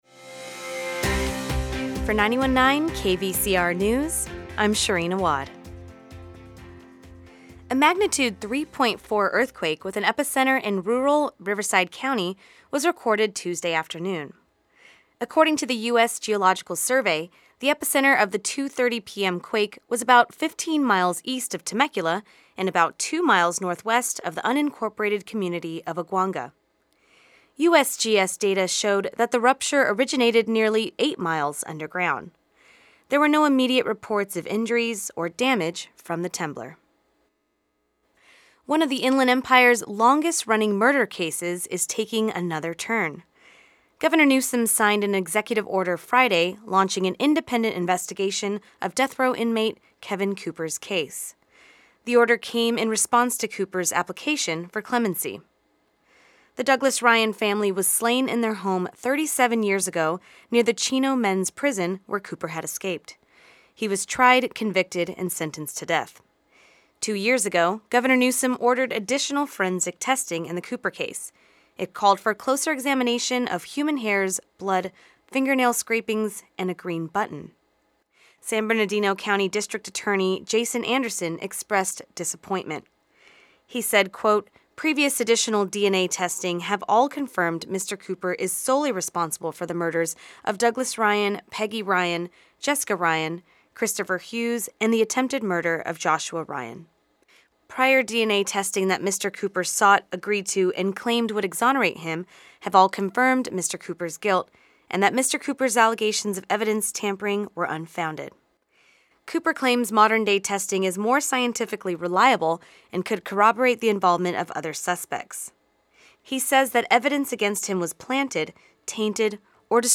The Midday News Report